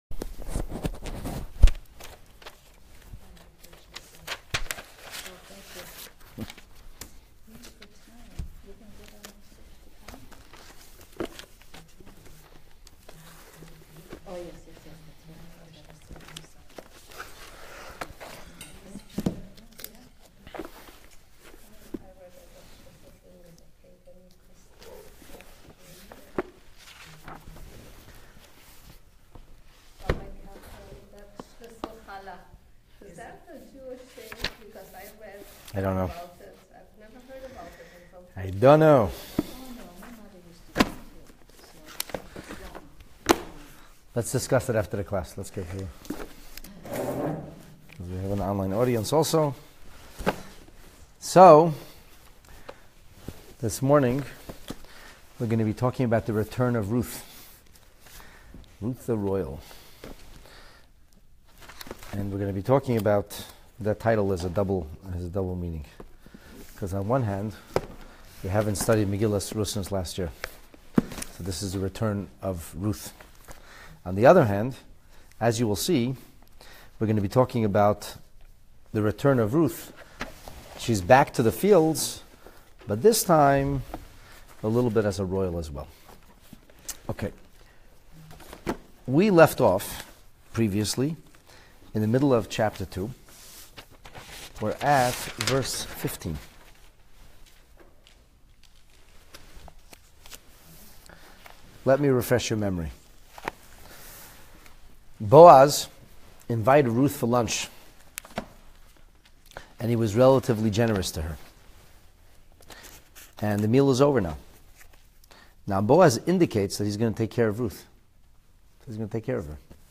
Megillat Rut in Depth: Chapter 2, Lesson 6 (PT 14) Ruth’s Return to Royalty.